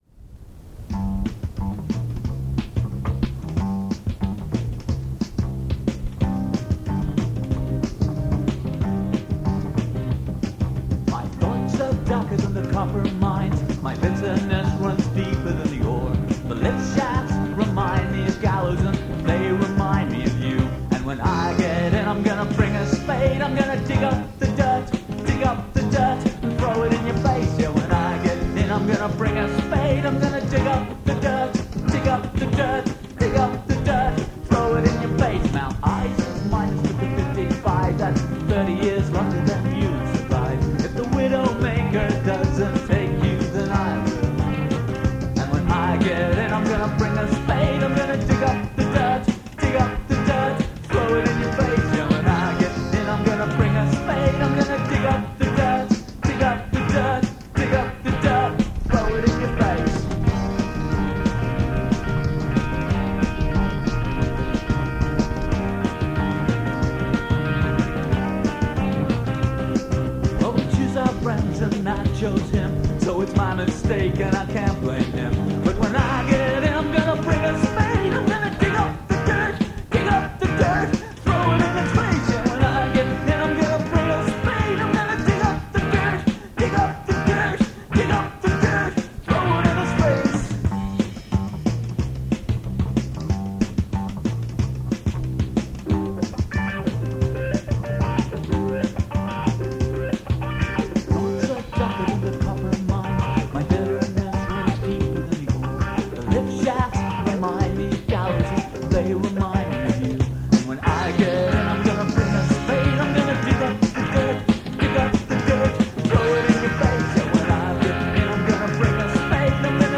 demos the band recorded in 1990